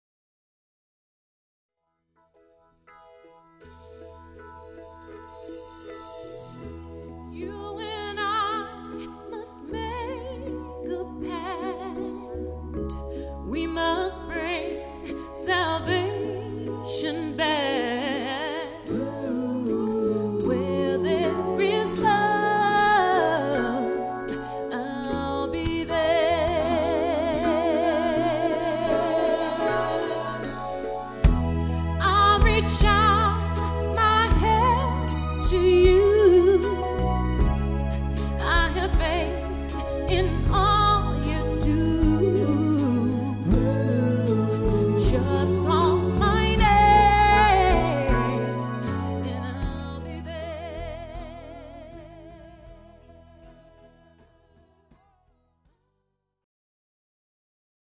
80's, 90's & 00's Music